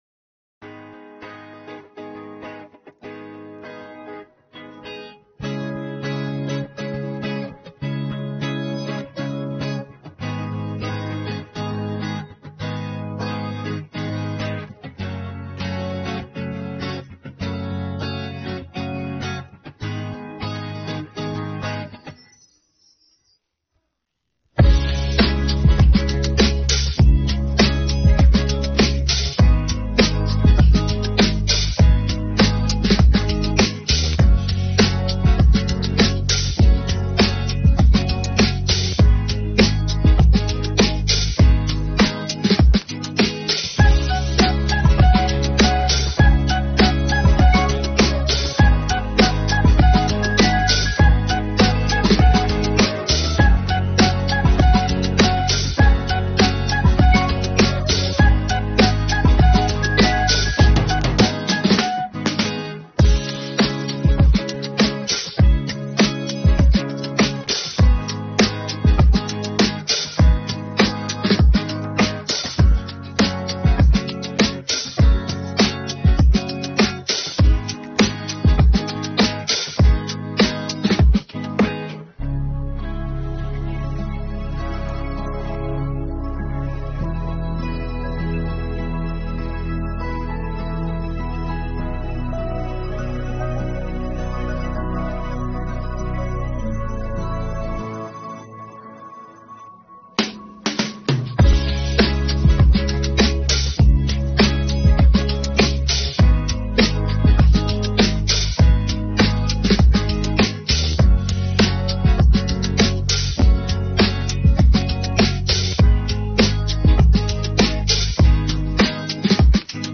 українська Караоке версія
Енергійний ритм